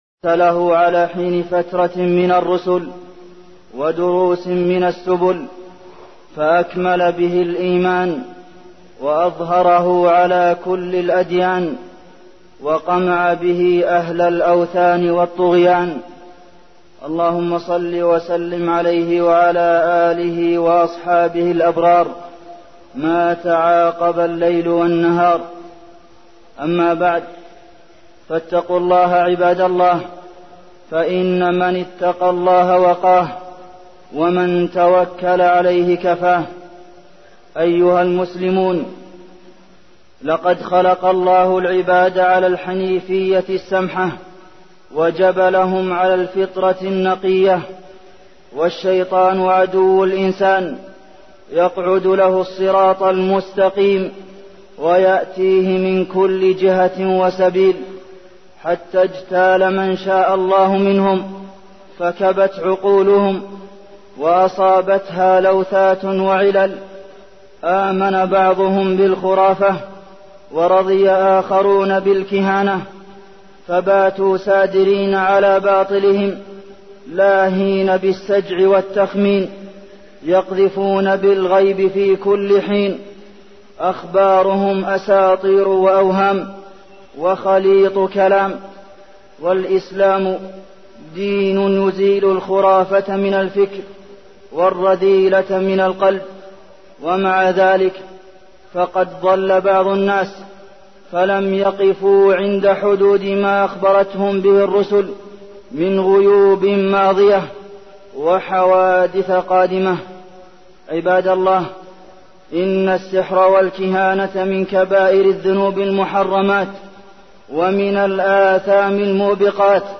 تاريخ النشر ١٥ شعبان ١٤١٩ هـ المكان: المسجد النبوي الشيخ: فضيلة الشيخ د. عبدالمحسن بن محمد القاسم فضيلة الشيخ د. عبدالمحسن بن محمد القاسم السحر